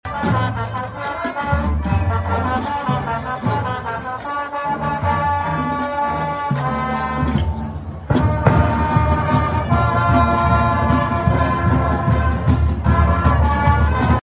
Welch Stadium was awash in color and sound for Emporia State’s annual Marching Festival.
3772-ehs-band-2.mp3